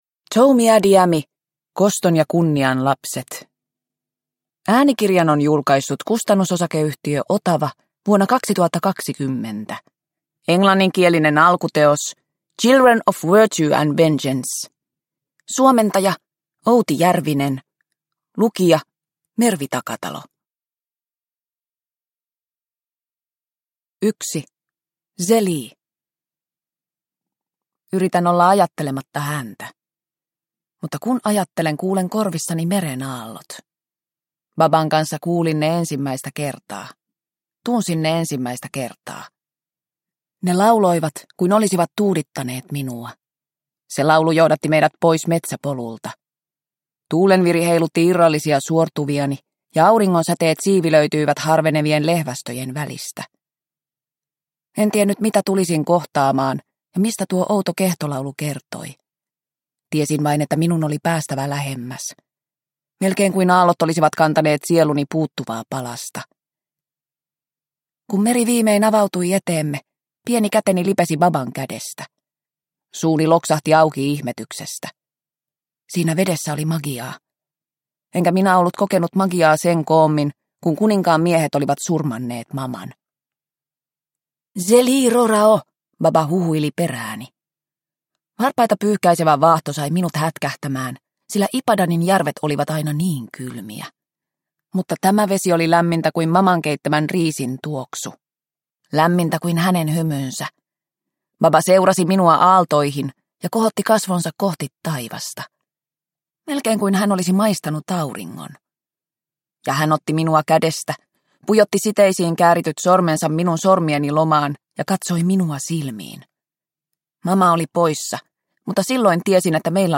Koston ja kunnian lapset – Ljudbok – Laddas ner